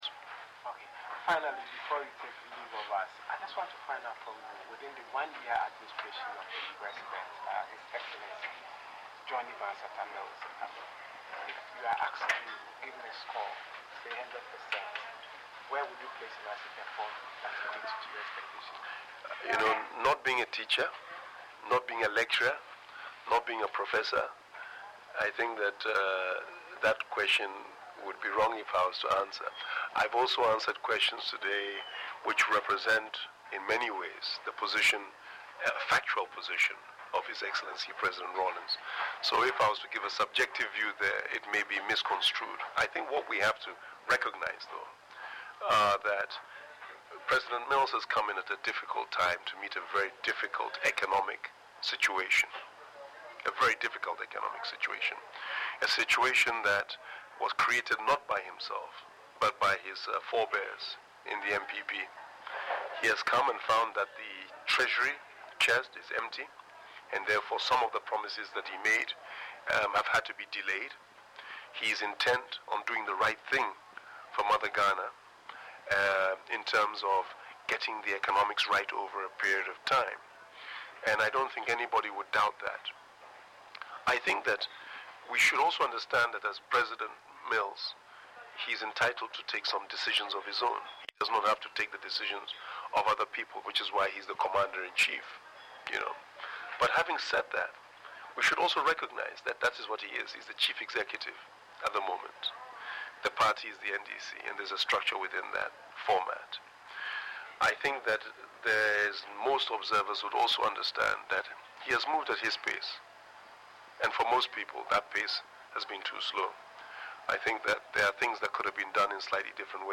Interview
was held at the NDC National Delegates Conference in Tamale.